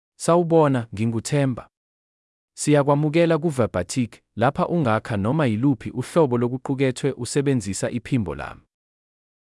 ThembaMale Zulu AI voice
Voice sample
Listen to Themba's male Zulu voice.
Male
Themba delivers clear pronunciation with authentic South Africa Zulu intonation, making your content sound professionally produced.